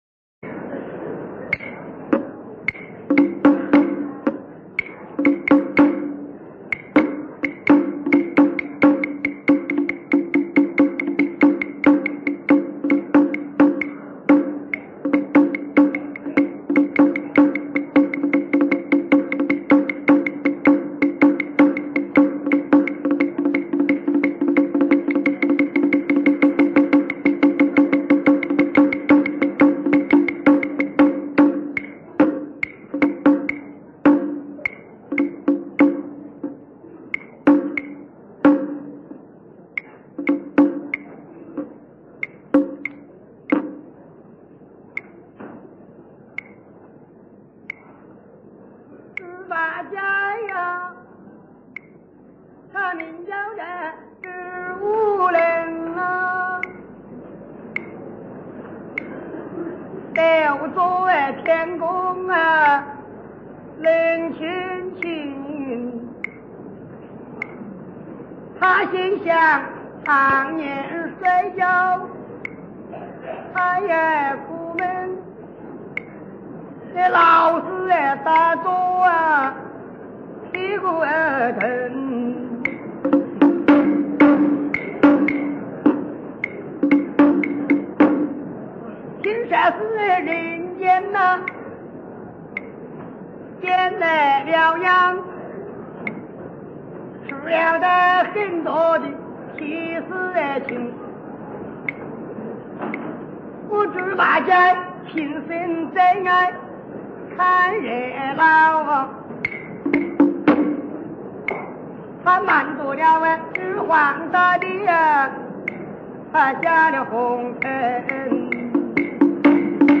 蘊生自民間土壤的說唱藝人
十八段原味酣暢的曲藝聲腔